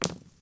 dieThrow2.ogg